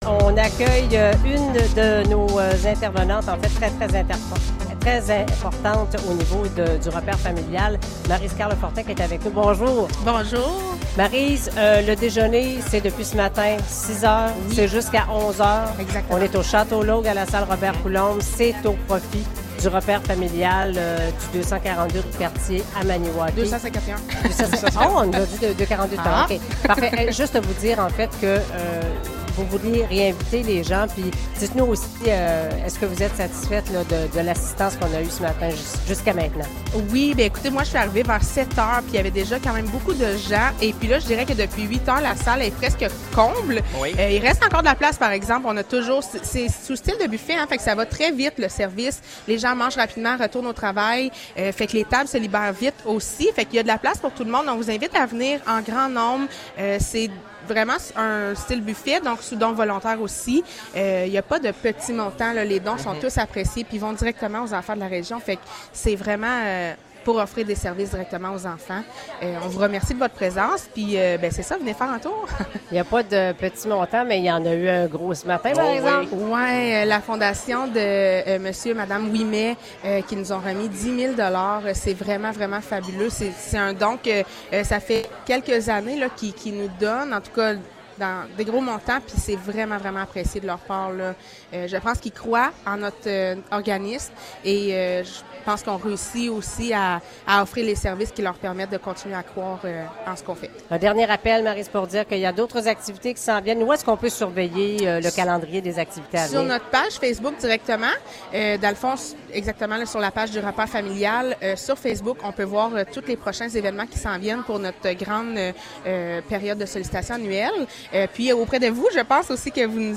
En direct du Déjeuner des enfants : Entrevue